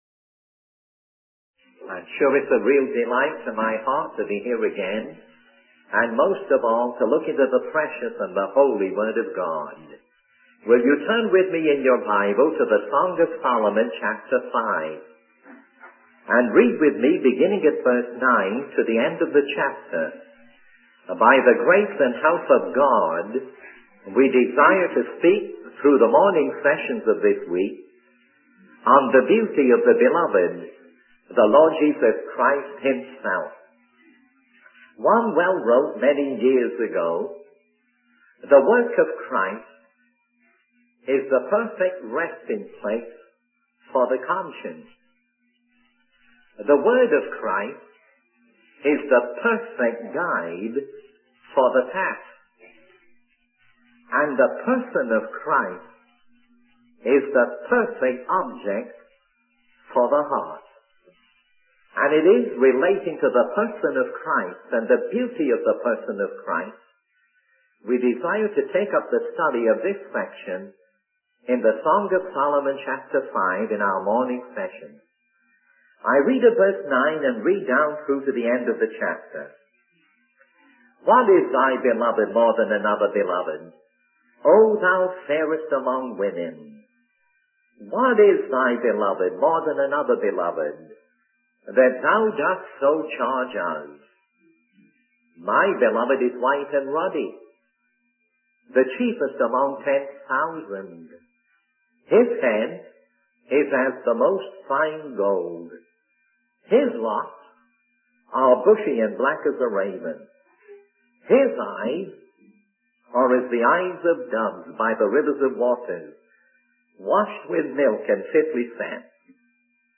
In this sermon, the speaker focuses on the beauty of the beloved, referring to Jesus Christ. The sermon is based on the Song of Solomon, specifically Chapter 5.